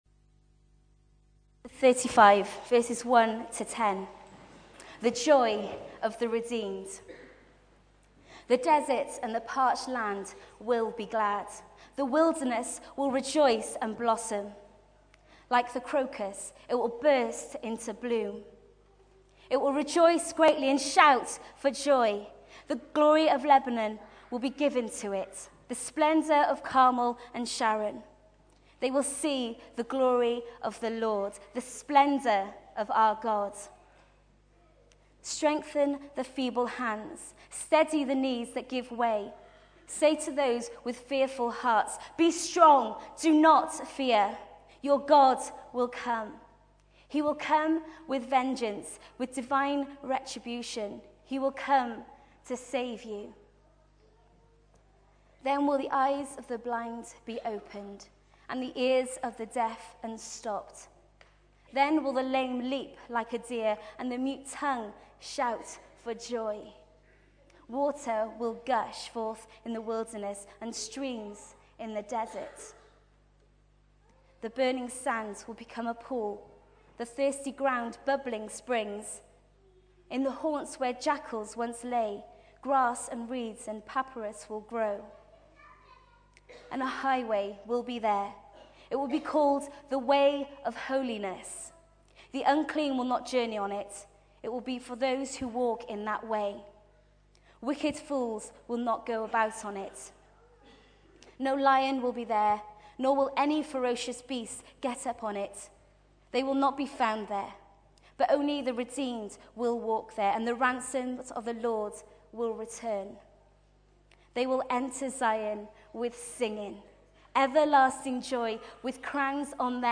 201209carolservice.mp3